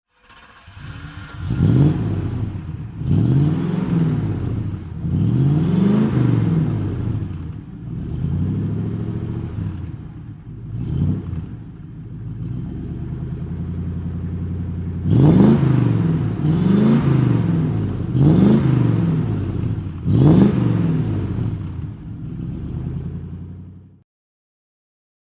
Many pictures and a sample of the engine sound round off the report.
Triumph Stag (1970) - Start- und Laufgeräusch